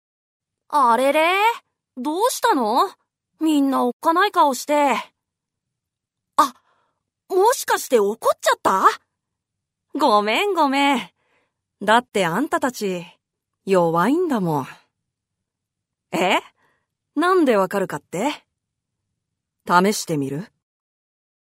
Voice Sample
セリフ2